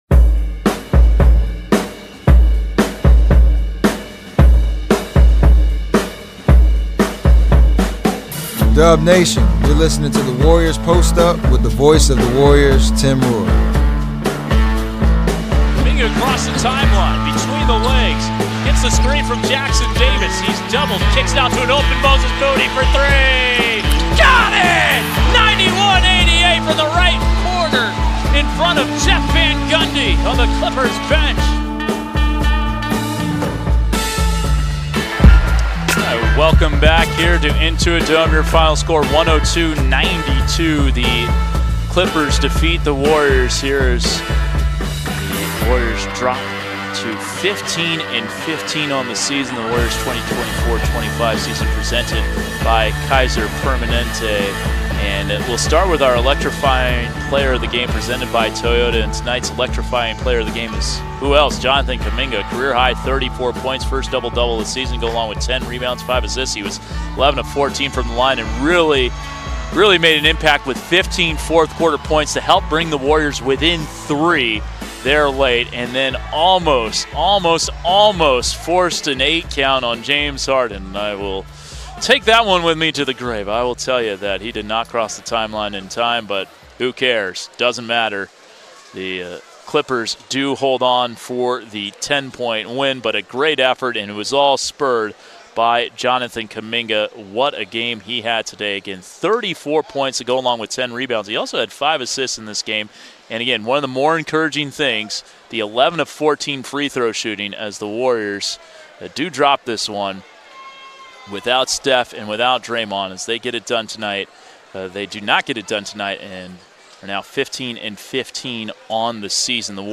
Official Golden State Warriors shows with interviews and analysis direct from the team, focusing on topics from in and around the NBA